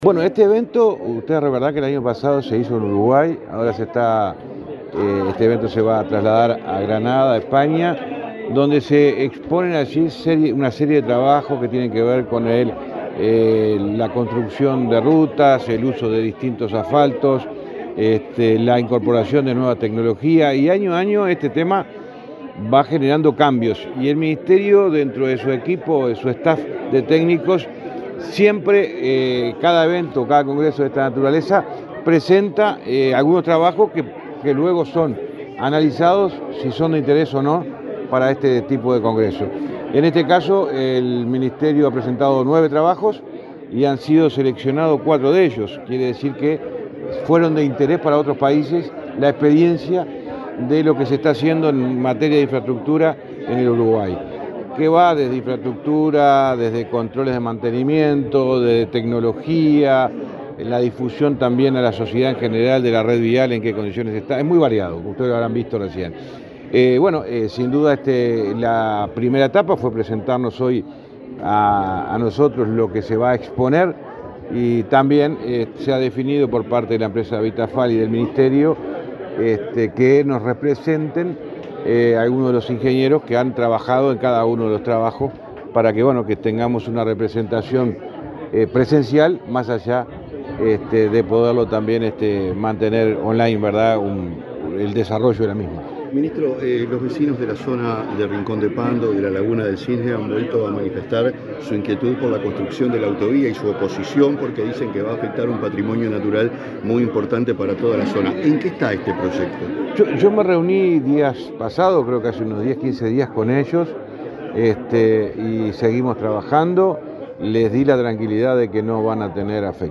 Declaraciones del ministro de Transporte, José Luis Falero
El ministro de Transporte, José Luis Falero, dialogó con la prensa, luego de participar, este jueves 14 en Montevideo, de la exposición de proyectos